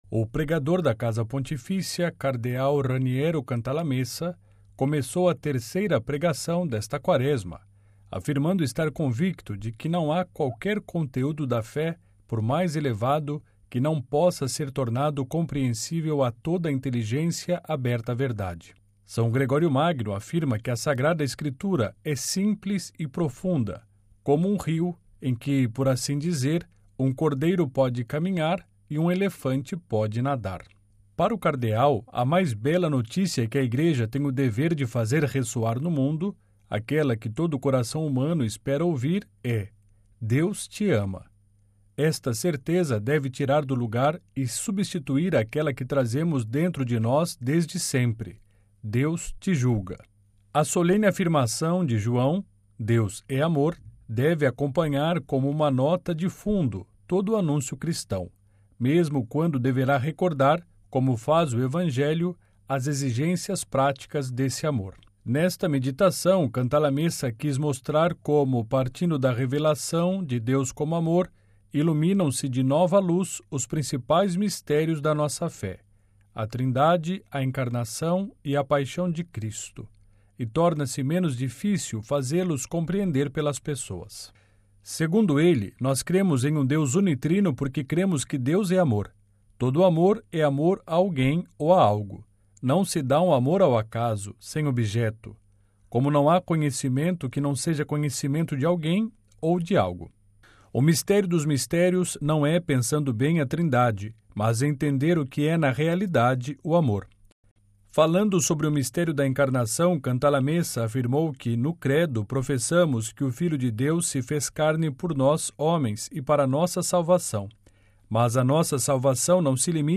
Terceira pregação da Quaresma de 2023